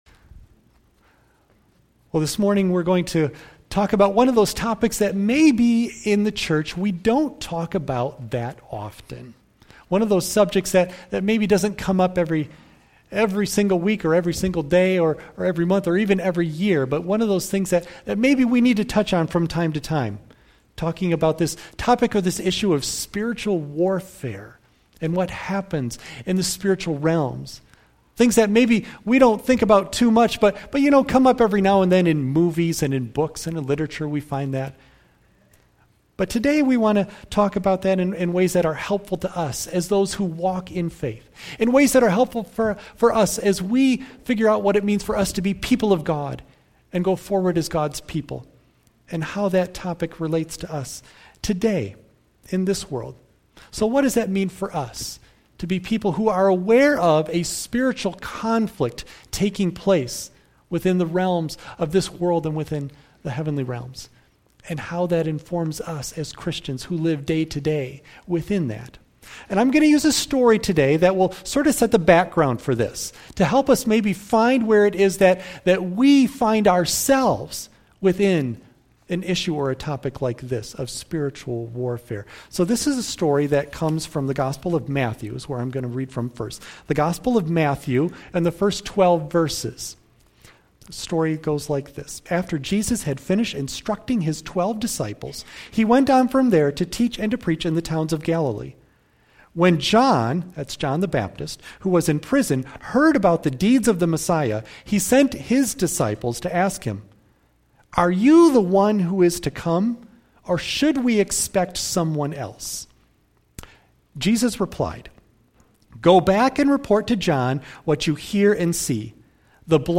John 10:10 Service Type: Sunday AM Bible Text